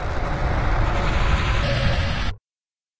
描述：过度处理的低音打击。
标签： 低音 水下
声道立体声